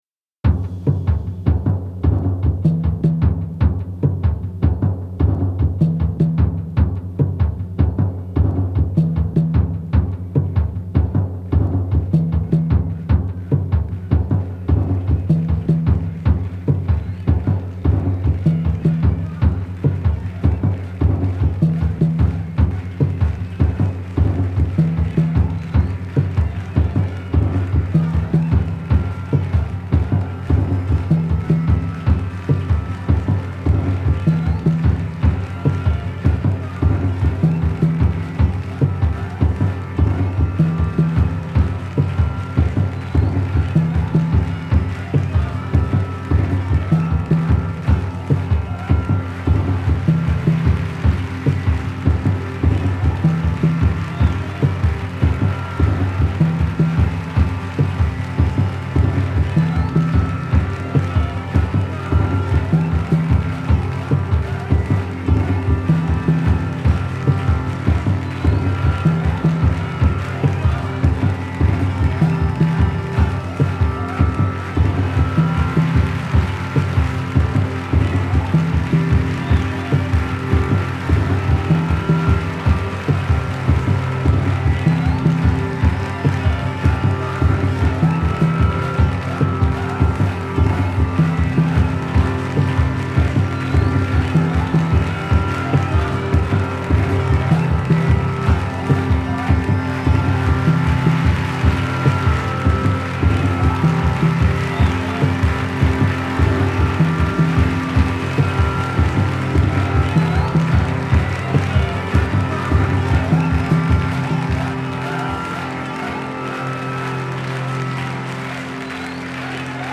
A 36-minute companion mix-tape of avant-jazz soundscapes